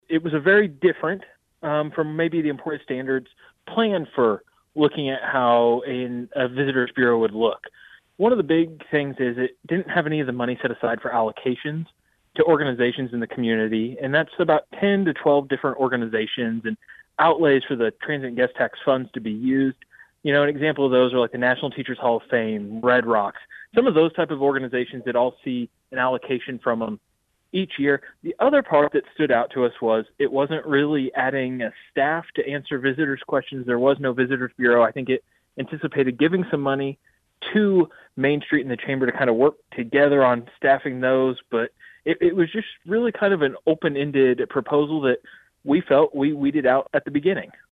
During discussions on the matter as part of the Emporia City Commission’s recent study session Wednesday afternoon, Commissioner Susan Brinkman sought clarification as to why the city’s proposal had not been scored.